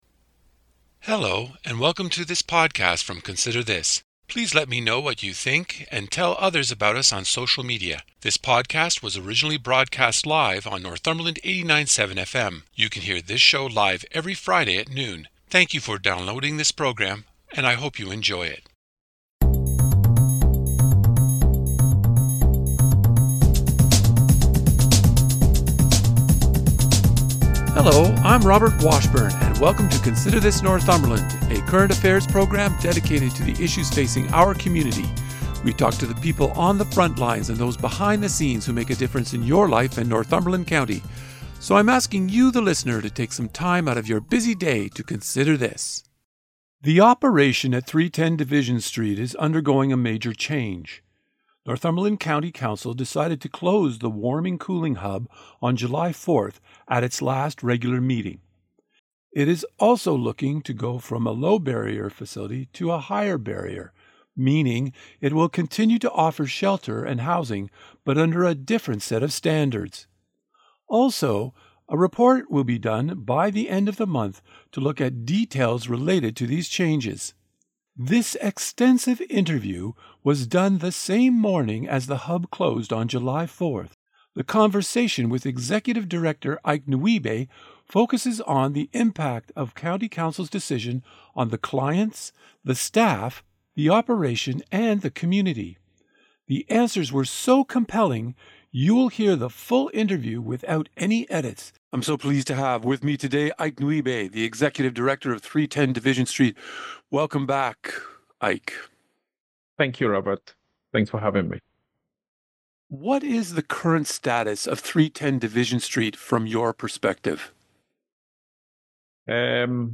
The answers were so compelling you will hear the full interview without any edits, taking up the entire show.